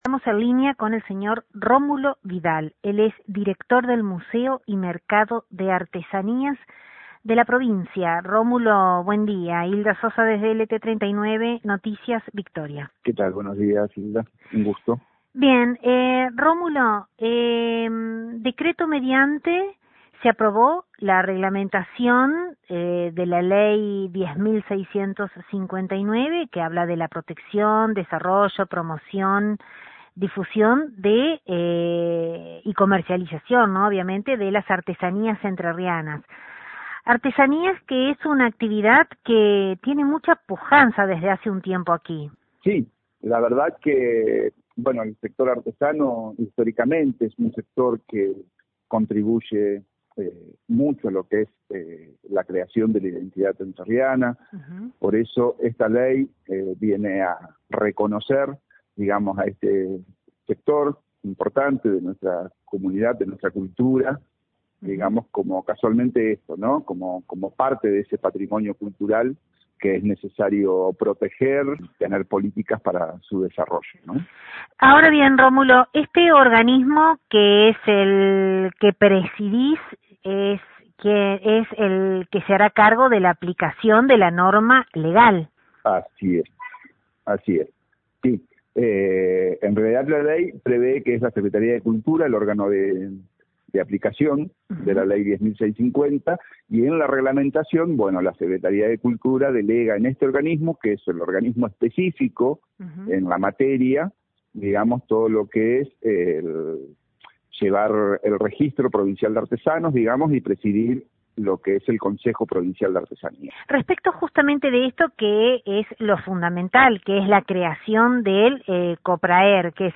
Desde LT39 NOTICIAS, dialogamos con el Director del mencionado organismo estatal, Rómulo Vidal; quien prima facie, hizo mención al decreto, que establece el primer paso para aplicar la ley provincial, estableciendo que se creará un Consejo Provincial de Artesanías de Entre Ríos (CoPrAER) que tendrá como propósito dictar una reglamentación interna en un plazo no mayor de 90 días desde la puesta en vigencia del Reglamento.